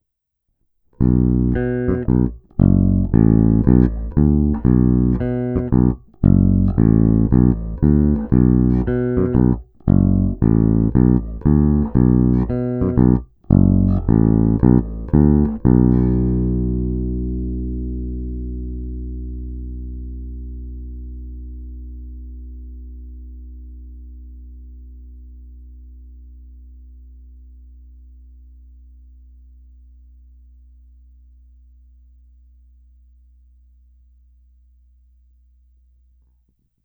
Zvuk je hodně dobrý, na krkový snímač tučný, kobylkový je zase středovější agresívnější, ale přesto nepostrádá tučný basový základ, přes jeho umístění blízko kobylky je až překvapivě použitelný i samostatně.
Není-li uvedeno jinak, následující nahrávky jsou provedeny rovnou do zvukové karty s plně otevřenou tónovou clonou. Nahrávky jsou jen normalizovány, jinak ponechány bez úprav.
Snímač u kobylky